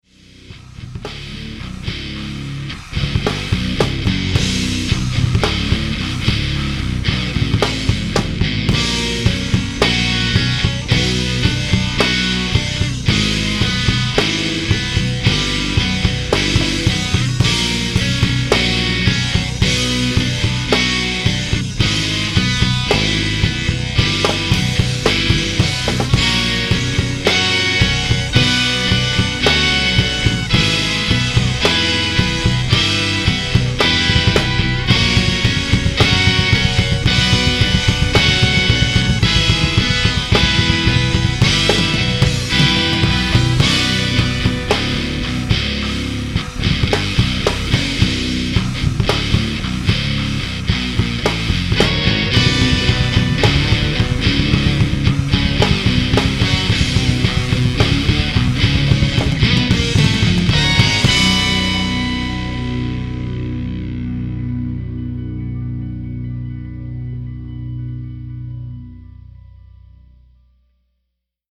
So, when the “When In Doubt” rule kicked in for this one (When in doubt, go fast and/or heavy), I decided to bust out the ol’ 7-string guitar and get ridiculously evil. The result: A recording that, in the middle section, features six guitar tracks going at once. Here is that bit again, without vocals.